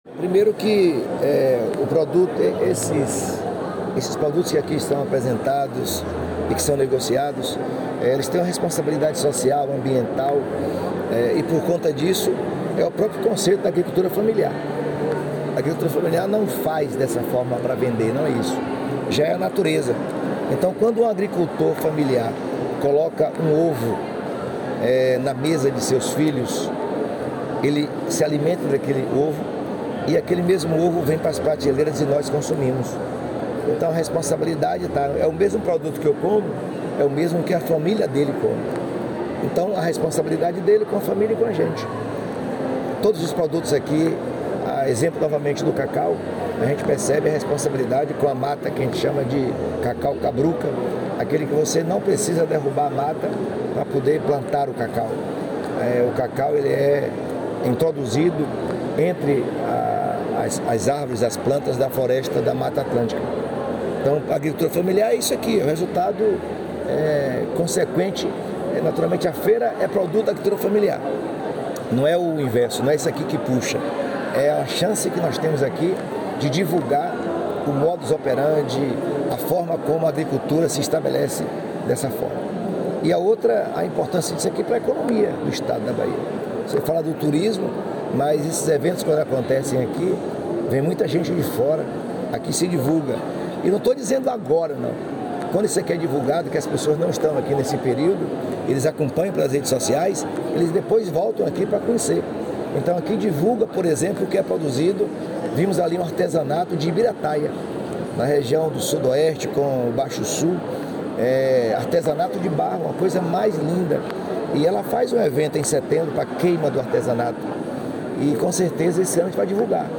🎙Jerônimo Rodrigues, Govenador da Bahia